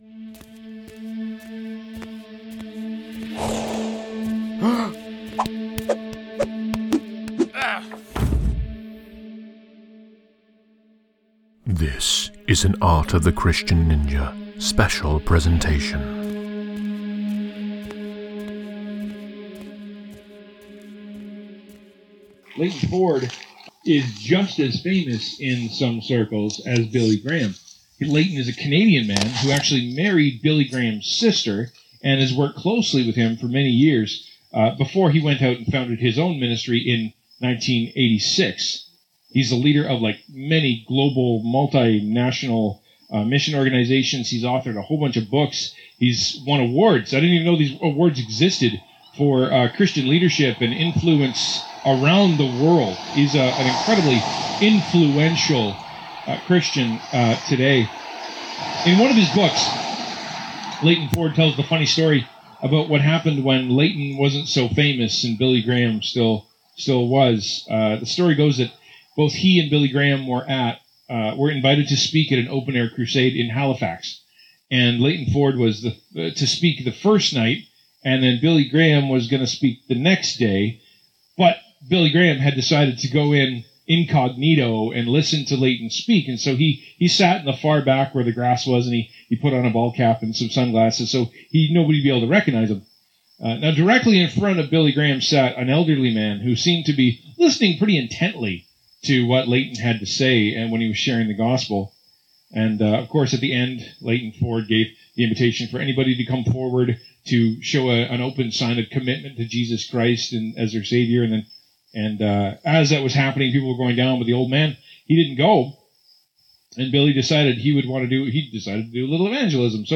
*This was an outdoor service so the audio is a little off.